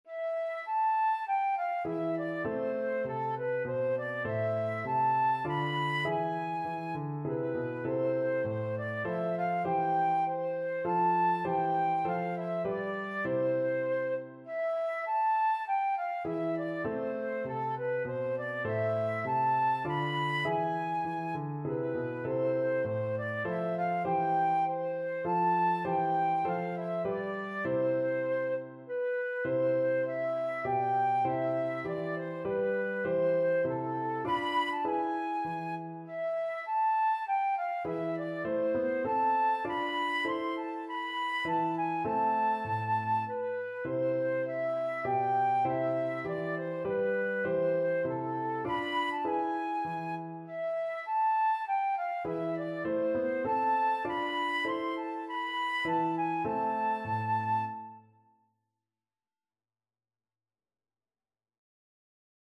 3/4 (View more 3/4 Music)
Moderato
Classical (View more Classical Flute Music)